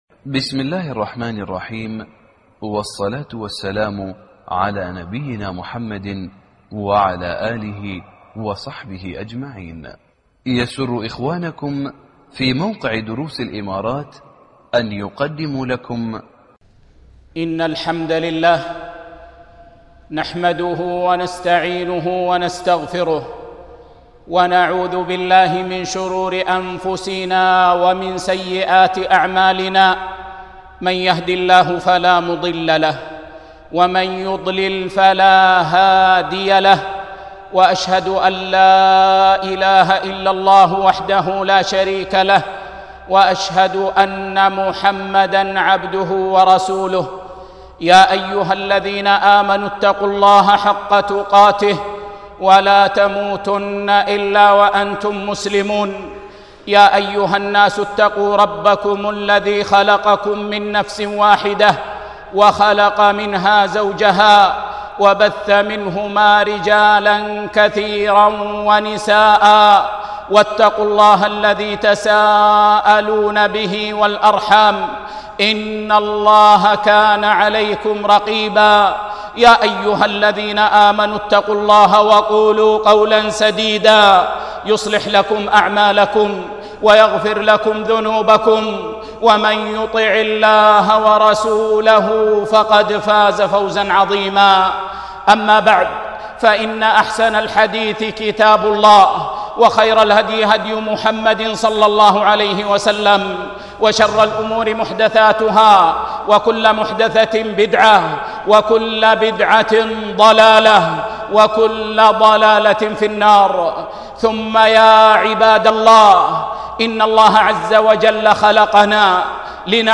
خطبة بعنوان